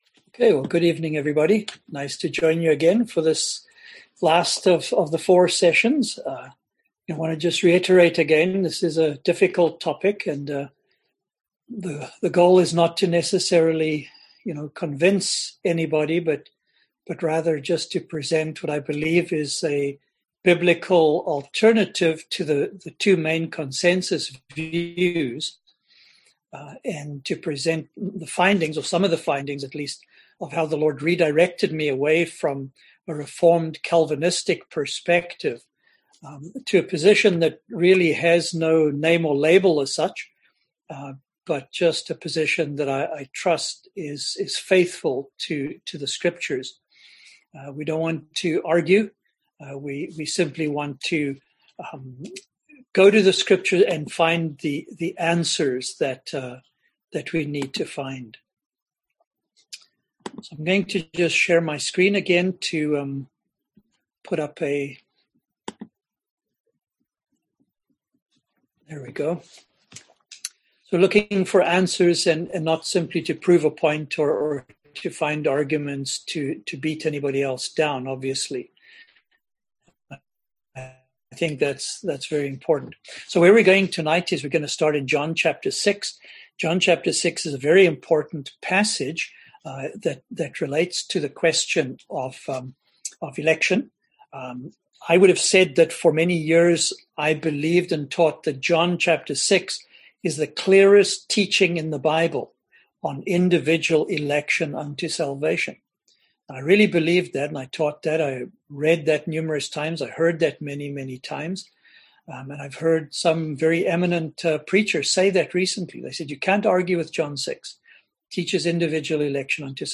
Service Type: Seminar Topics: Election , Predestination